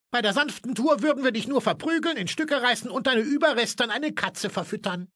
For the video game of one of America’s most successful TV series, Effective Media was able to employ the entire German voice-over cast along with the director.